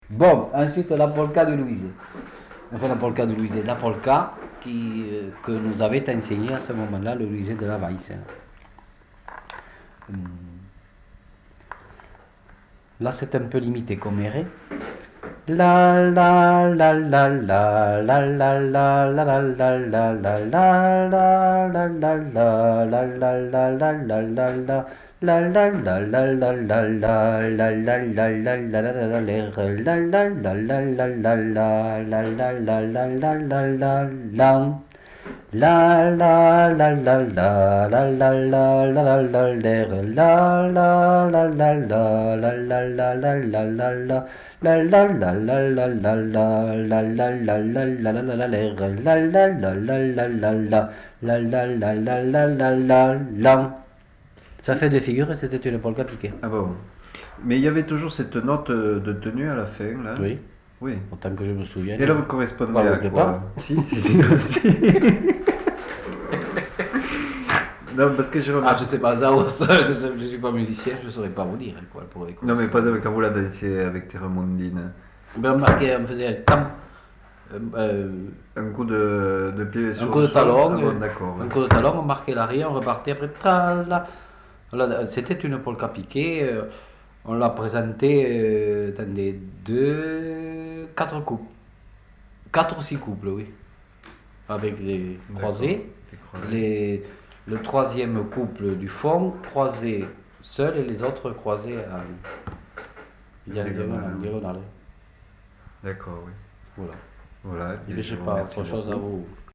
Aire culturelle : Lauragais
Genre : chant
Effectif : 1
Type de voix : voix d'homme
Production du son : fredonné
Danse : polka